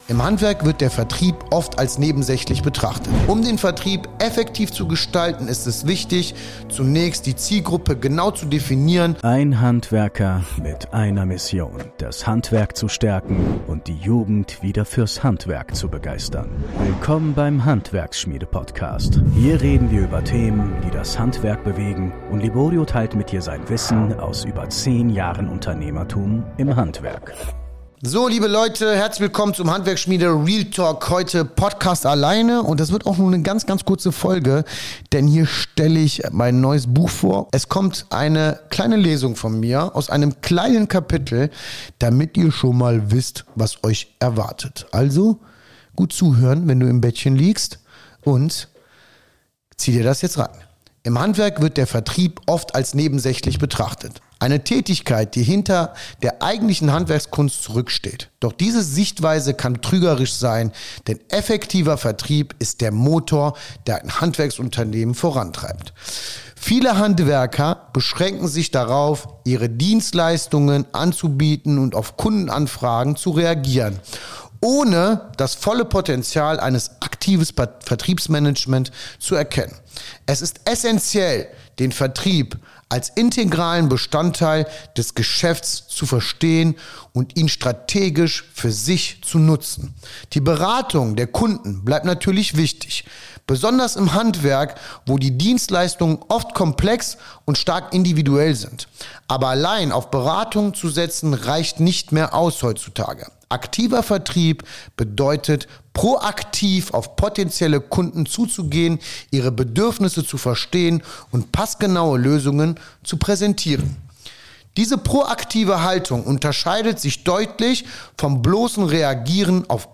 Das wertvollste Kapitel habe ich euch vorgelesen, viel